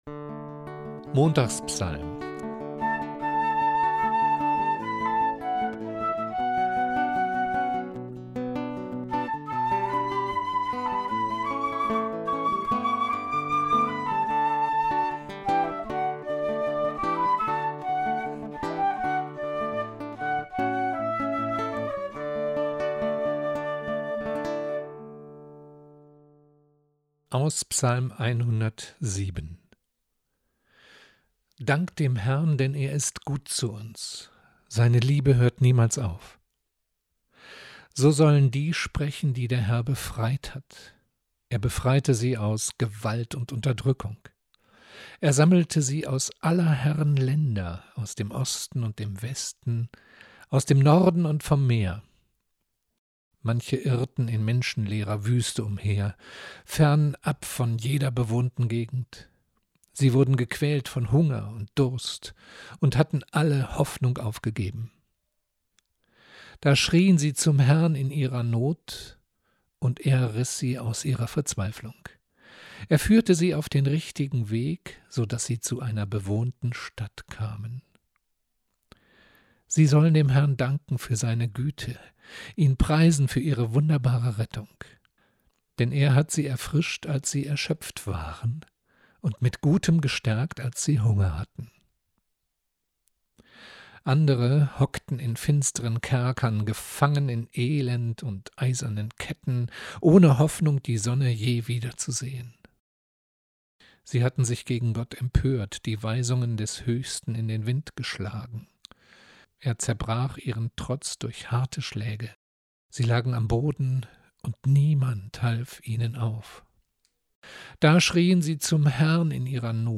Anhänge: Bibellesung_Ps_107_0426.mp3 ‹ Montags-Psalm Nach oben Montags-Psalm (31) vom 4.